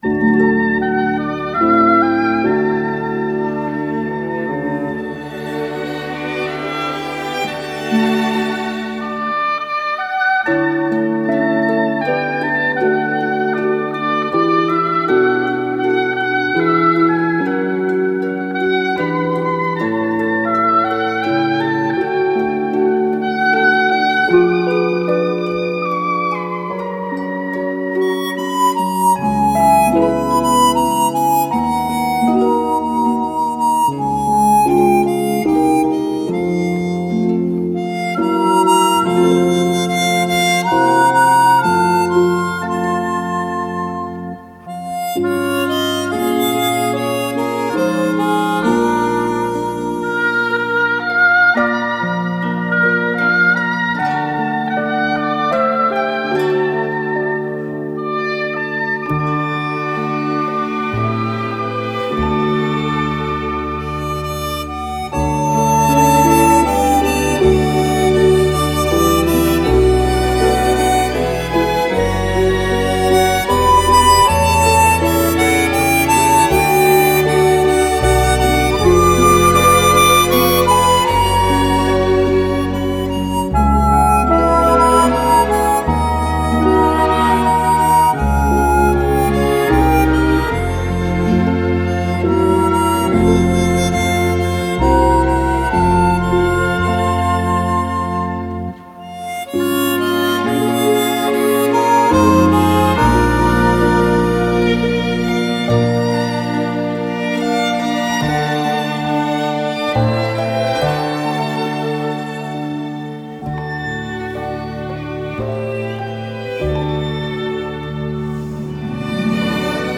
口琴演奏